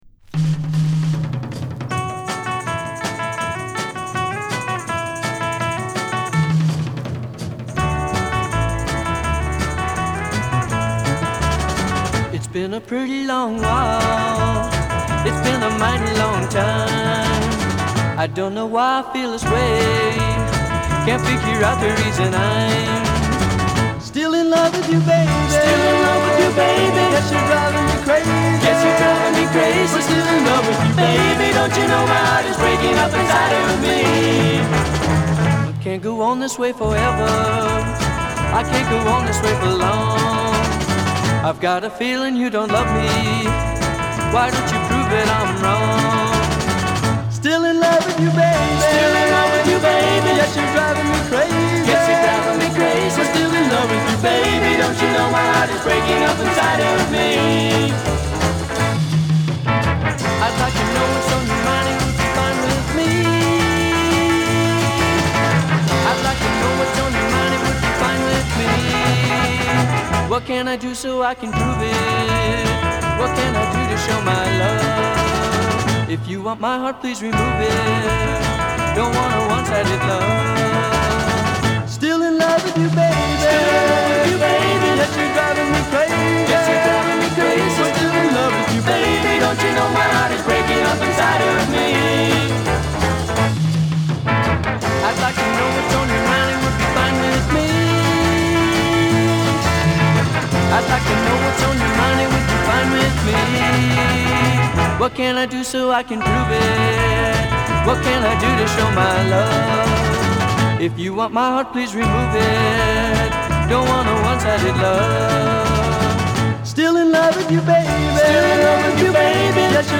タンバリンとジャングリーなギターが強調されたラフな演奏は、オリジナルよりずっとガレージ・パンクであると断言できる。
• GARAGE / PUNK / BEAT